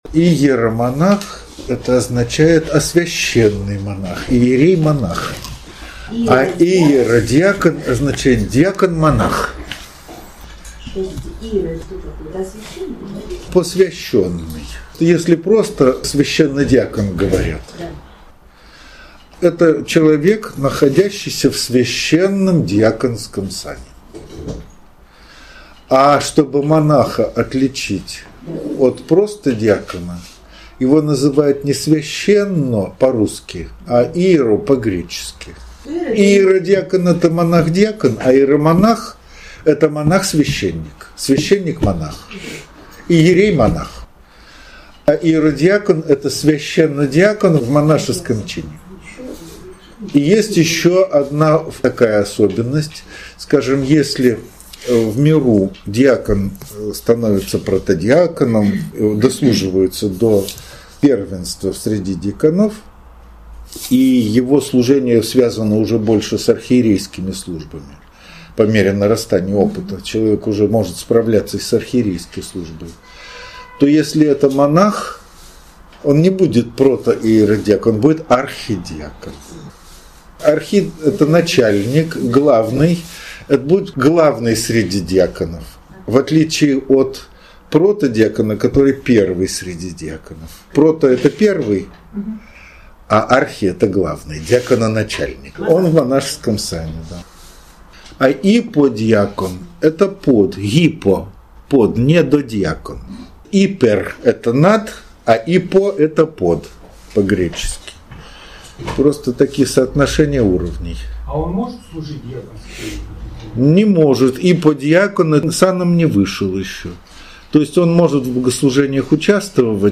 Ответы на вопросы
Лекция 2 ноября 2020 года.